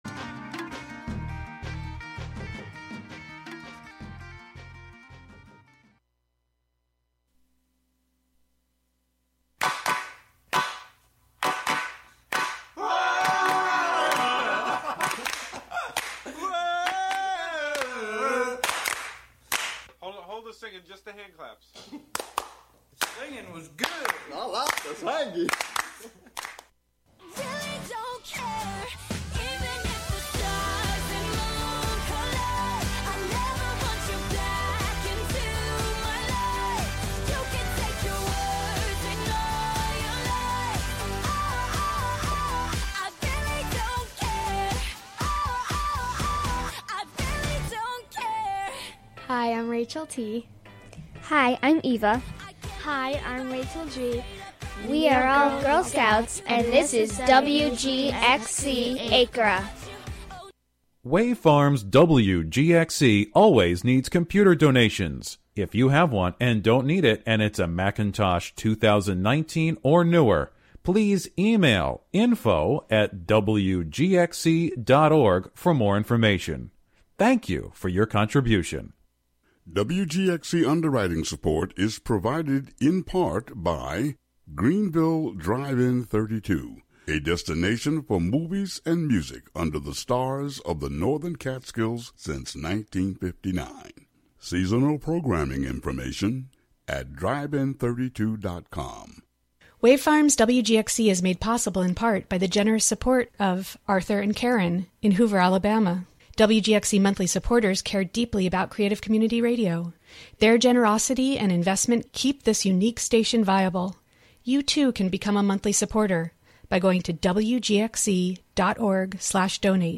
soul-stirring gospel music